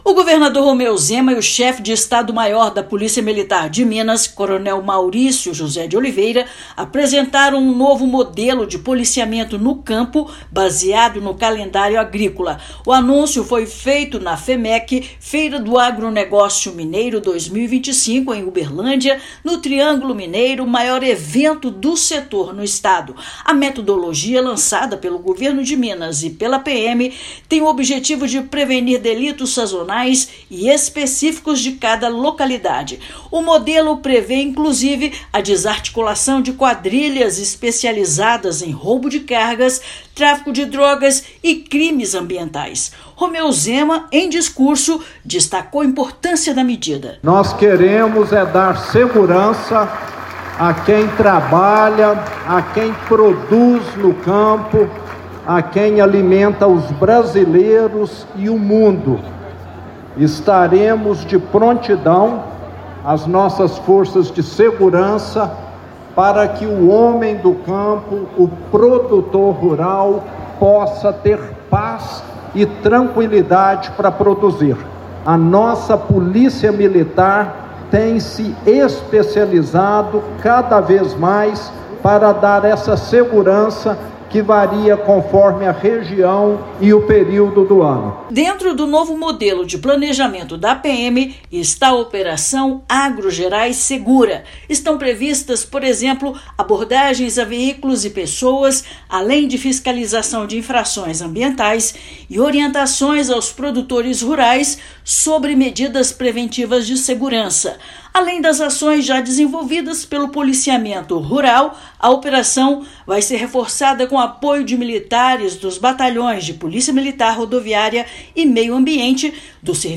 Iniciativa visa fortalecer as ações de prevenção e proteção, garantindo a segurança dos produtores e moradores do campo. Ouça matéria de rádio.